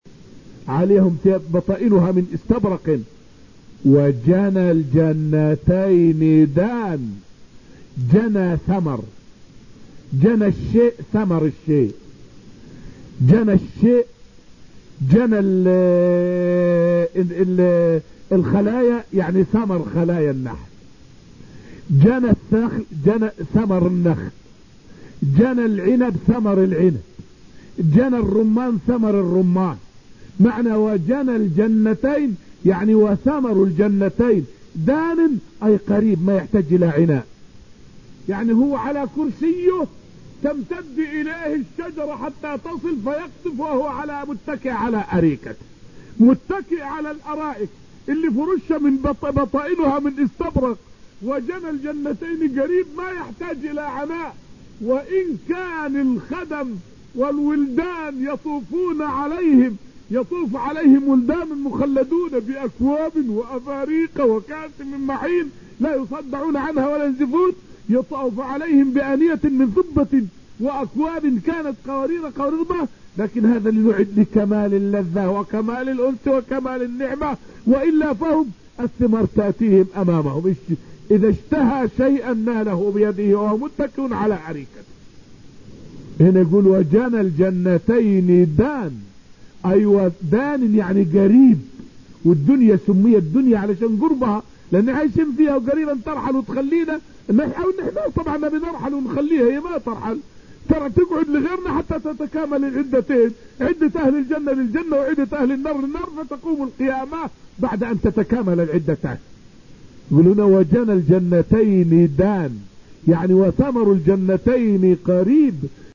فائدة من الدرس الحادي عشر من دروس تفسير سورة الرحمن والتي ألقيت في المسجد النبوي الشريف حول معنى قوله تعالى {ودنا الجنتين دان}.